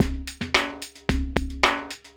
Index of /90_sSampleCDs/USB Soundscan vol.46 - 70_s Breakbeats [AKAI] 1CD/Partition B/26-110LOOP A